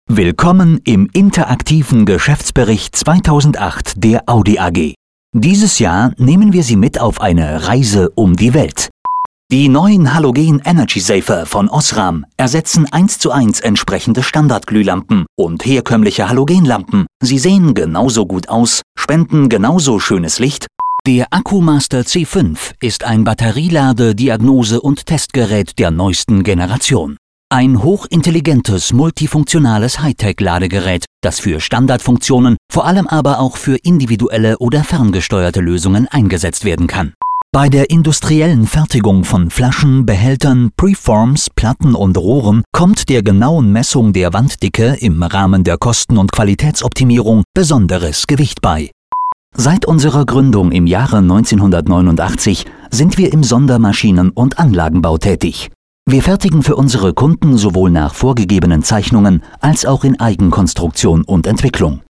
deutscher Sprecher für Werbespots, Hörspiele und Hörbücher, Podcasts, E-Journals und Business Radio Special: Münchener Dialekt Bayerisch
Sprechprobe: Werbung (Muttersprache):
german voice over talent